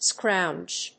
発音記号
• / skrάʊndʒ(米国英語)